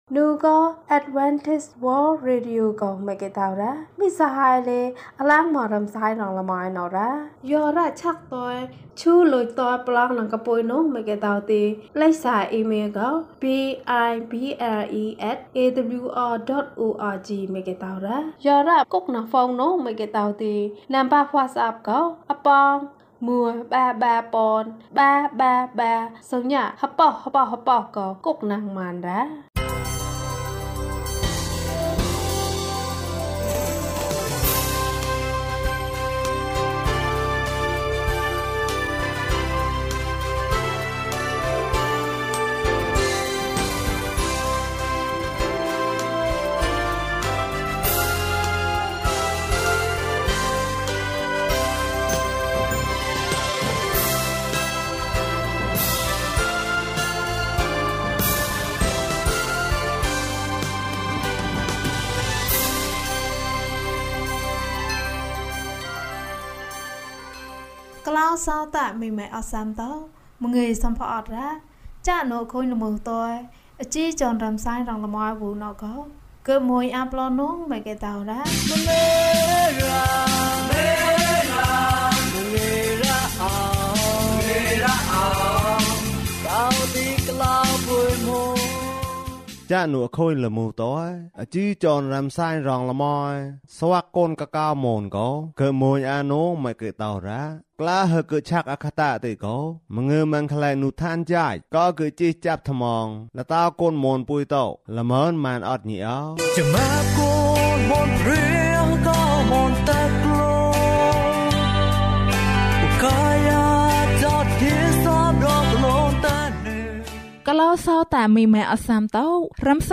လူငယ်များအတွက် သတင်းစကား။၀၃ ကျန်းမာခြင်းအကြောင်းအရာ။ ဓမ္မသီချင်း။ တရားဒေသနာ။